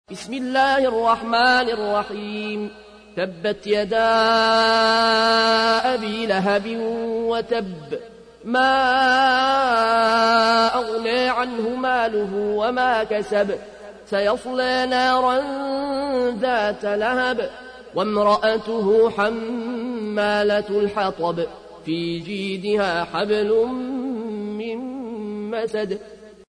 تحميل : 111. سورة المسد / القارئ العيون الكوشي / القرآن الكريم / موقع يا حسين